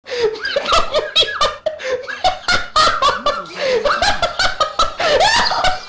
Play, download and share risa loca original sound button!!!!
risita.mp3